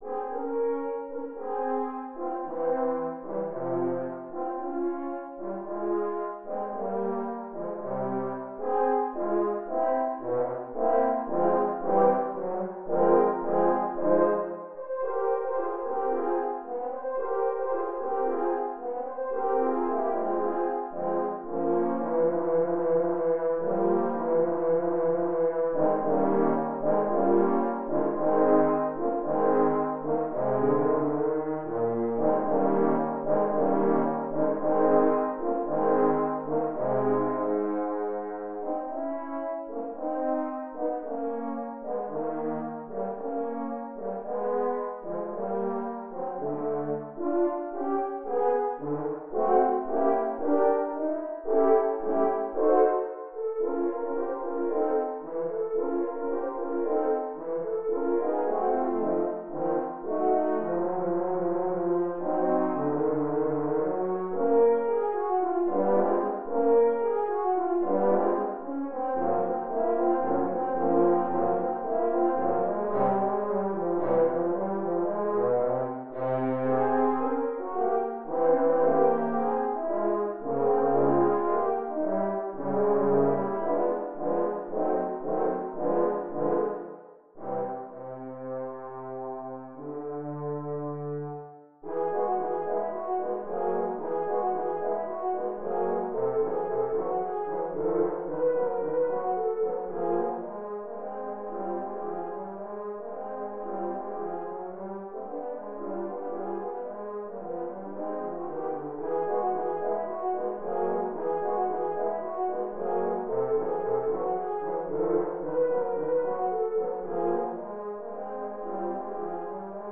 Arden Horn Quartet No. 6 (MIDI)